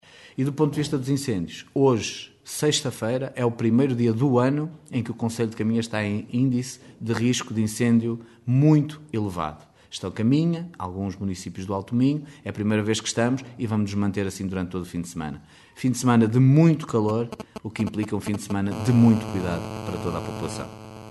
Miguel Alves, presidente da Comissão Distrital de Proteção Civil, a fazer o alerta à população.